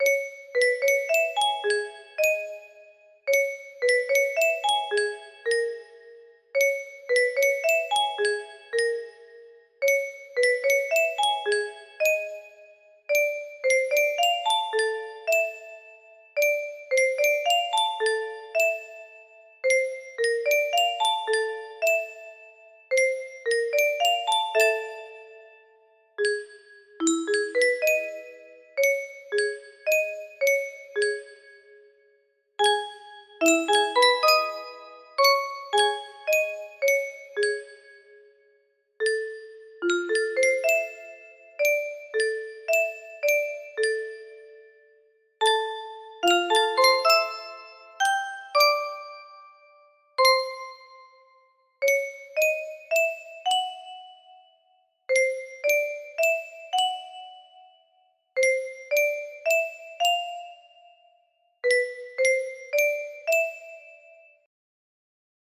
lost in the sky music box melody